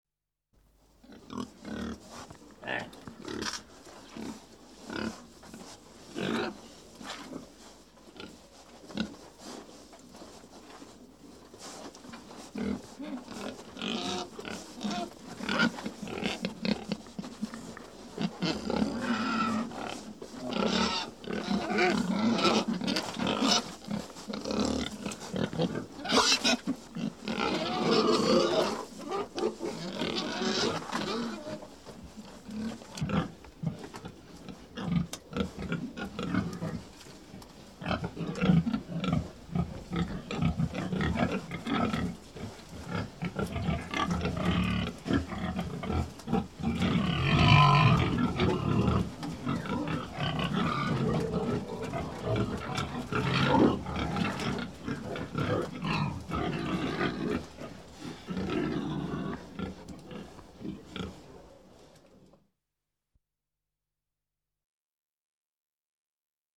maiale_pig01.mp3